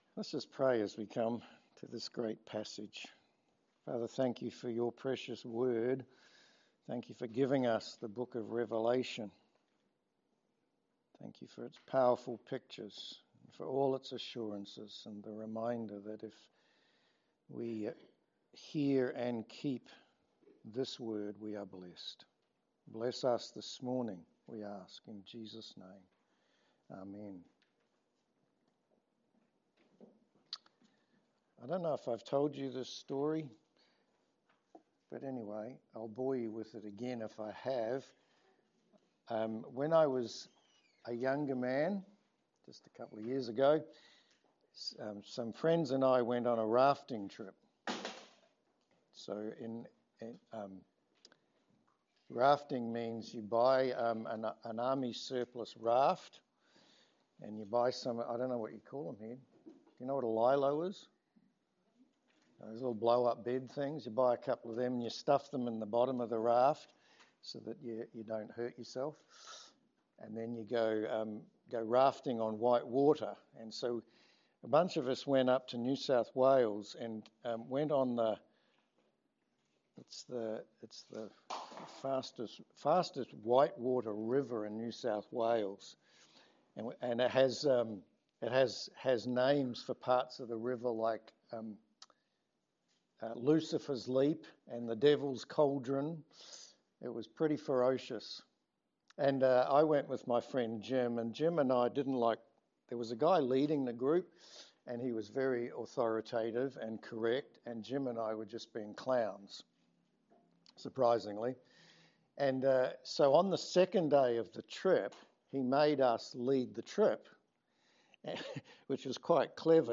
Revelation 20 Service Type: Sermon Sometimes we can think that Satan is more powerful than he really is.